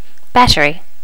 Normalize all wav files to the same volume level.
battery.wav